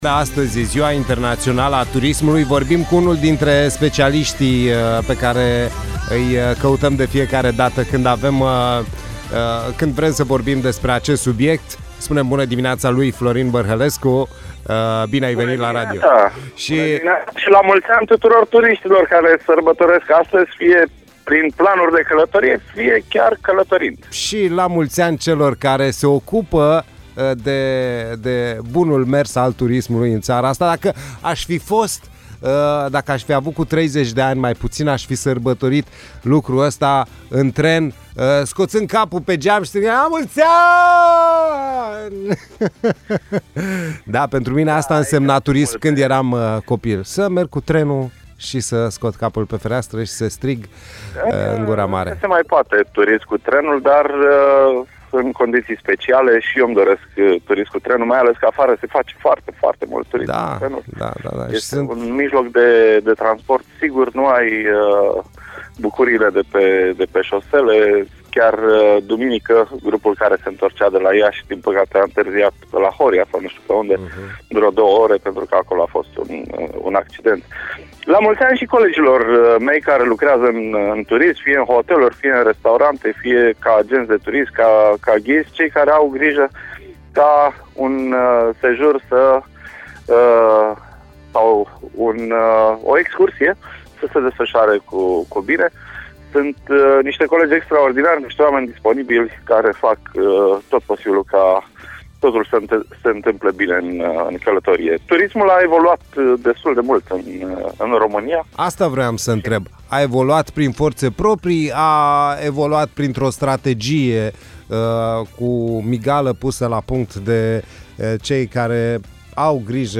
expert in turism a acordat un interviu pentru RadioHit în emisiunea Play the Day cu perspectiva sa asupra turismului din România